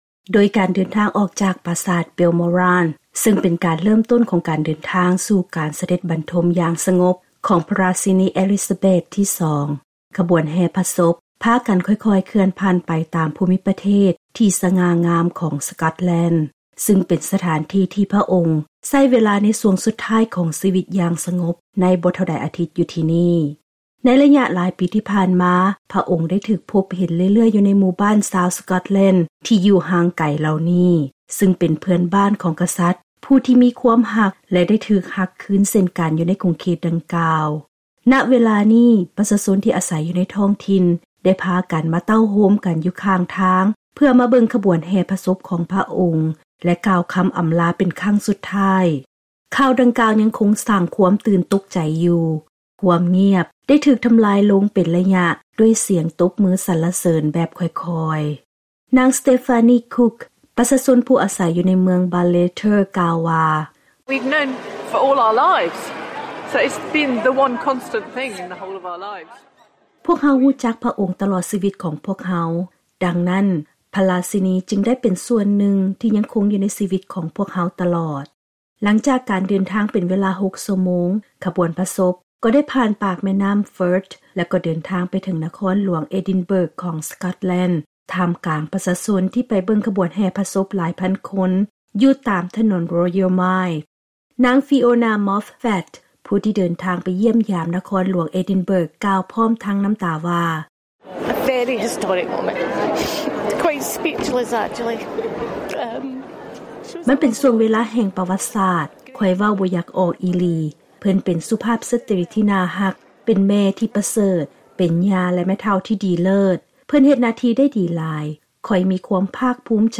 ຂ່າວດັ່ງກ່າວຍັງຄົງສ້າງຄວາມຕື່ນຕົກໃຈຢູ່, ຄວາມງຽບໄດ້ຖືກທໍາລາຍລົງເປັນໄລຍະດ້ວຍສຽງຕົບມືສັນລະເສີນແບບຄ່ອຍໆ.
The silence was gently broken by ripples of applause.